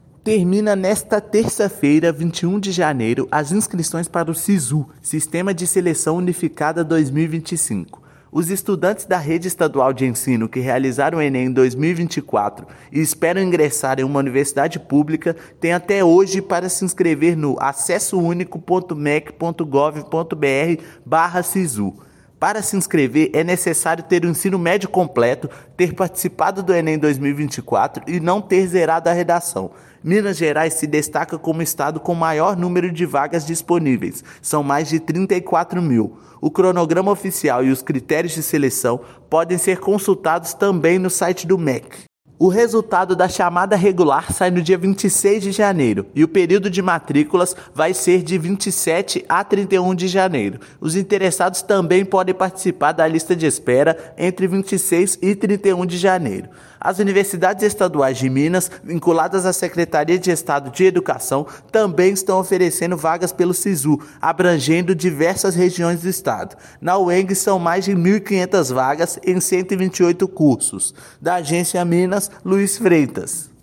Inscrições começam nesta sexta-feira (17/1) e vão até 27/1; Uemg e Unimontes somam mais de 2 mil vagas. Ouça matéria de rádio.